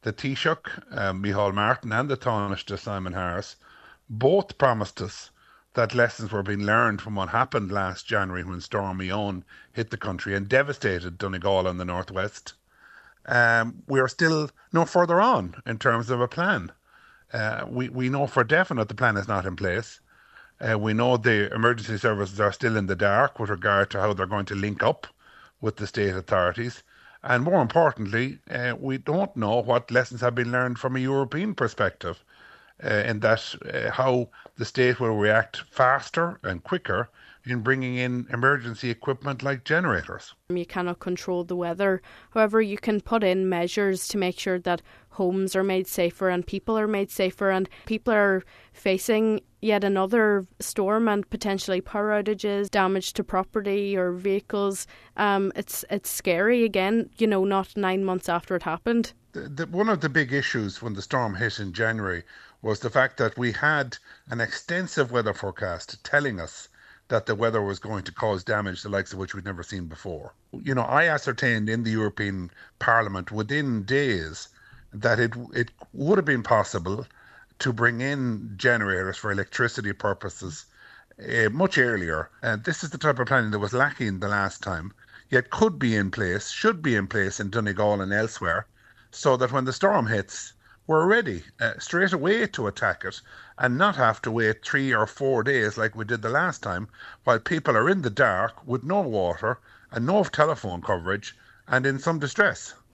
He says, with Storm Amy reaching the county today, Donegal people are still being left at risk by the Government: